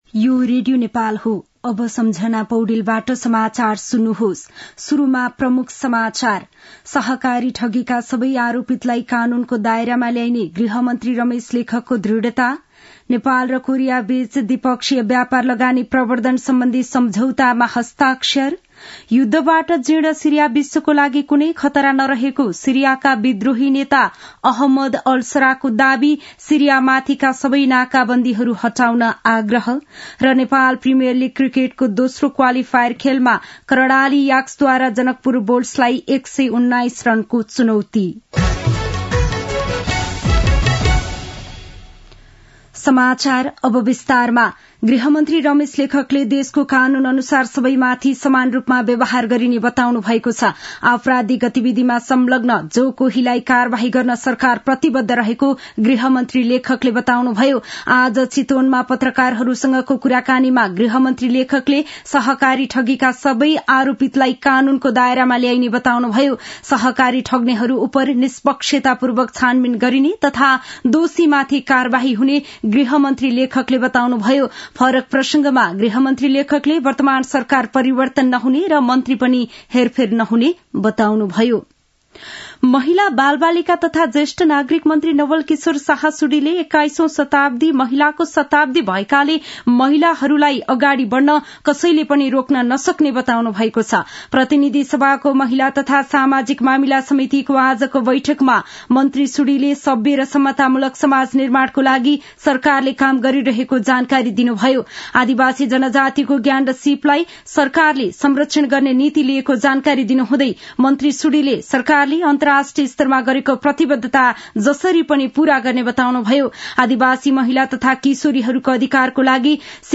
दिउँसो ३ बजेको नेपाली समाचार : ५ पुष , २०८१
3-pm-nepali-news-1-11.mp3